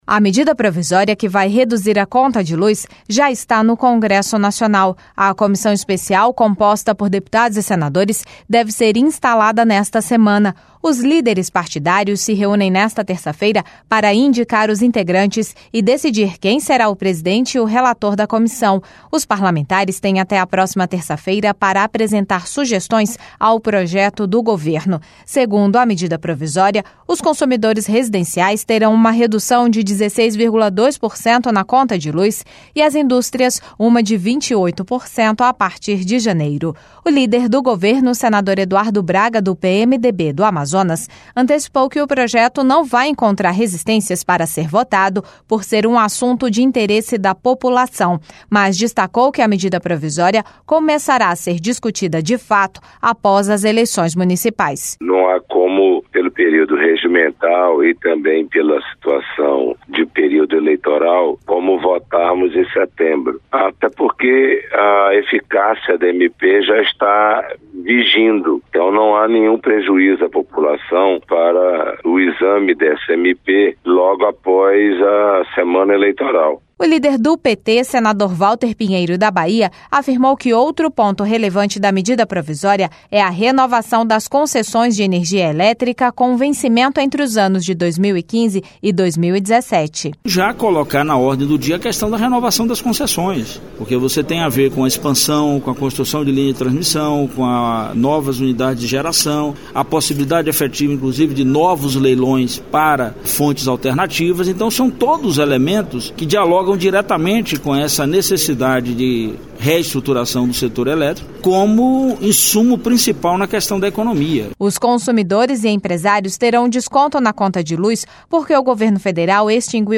Senador Eduardo Braga
Senador Walter Pinheiro